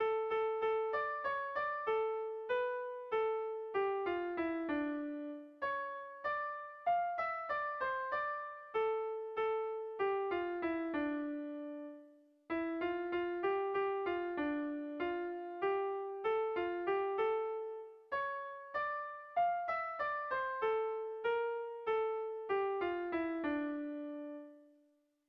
Irrizkoa
AB1DB2